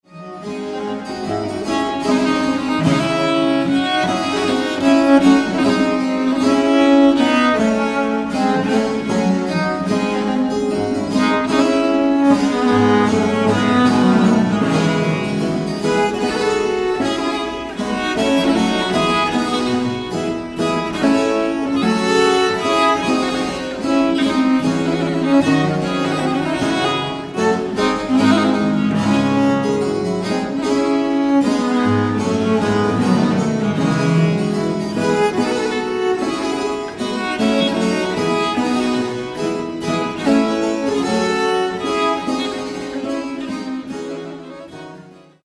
French viola da gamba repertoire